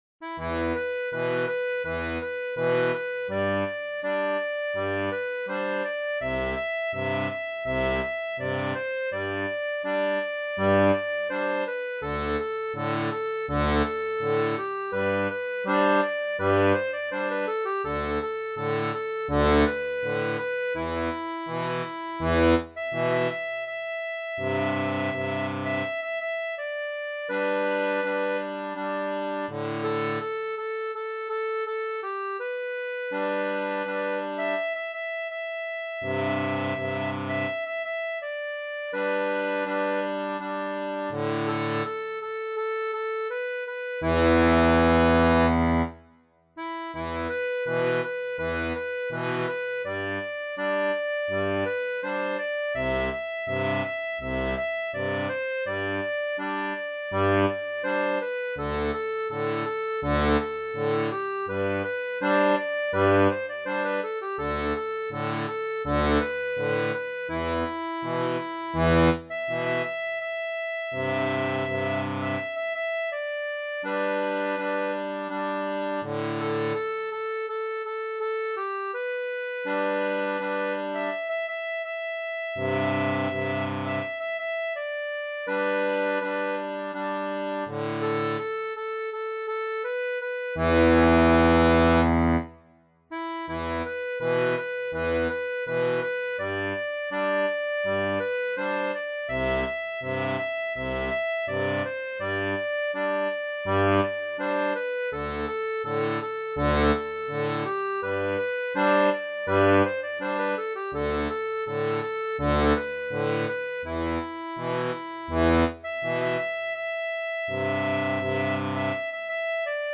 • Une tablature pour diato 2 rangs en Mi
Chanson française